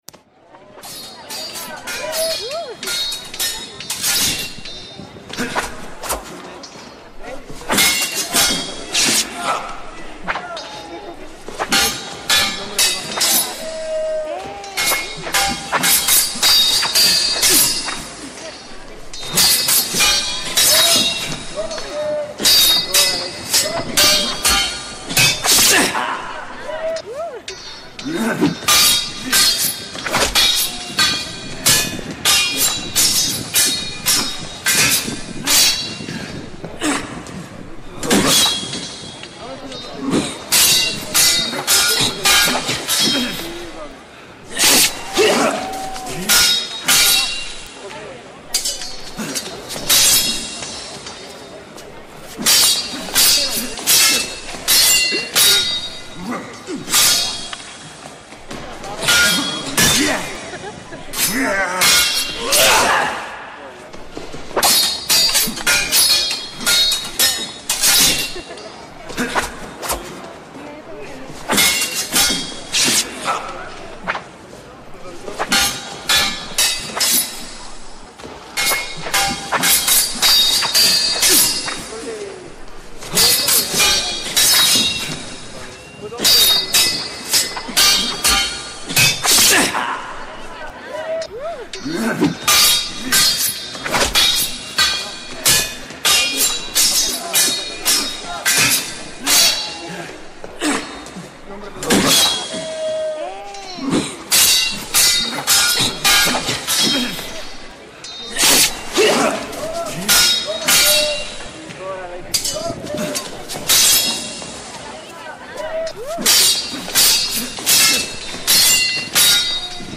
Mercado medieval de Ávila
Mejor disfrutar de las fotografías con el sonido de la lucha,
lucha-espadas.mp3